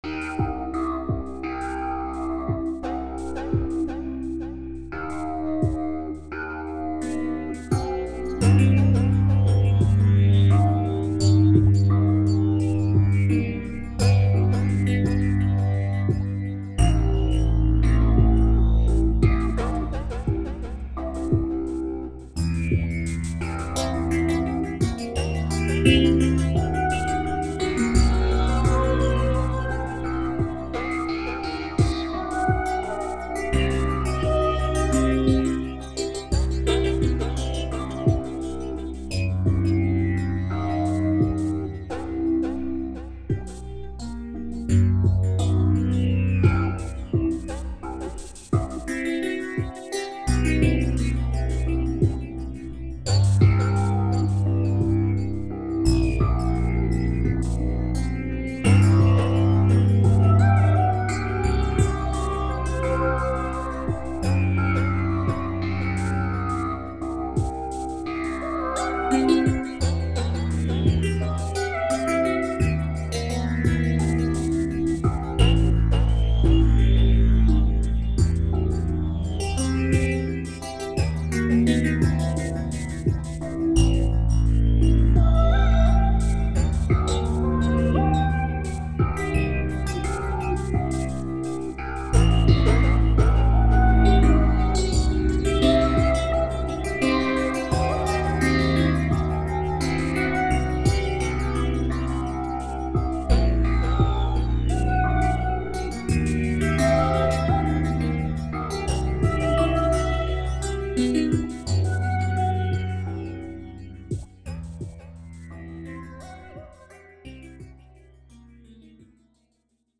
On obtient comme résultat une musique assez inhabituelle, structurée mais sans thème répétitif.
Ce sont 3 compositions "cousines".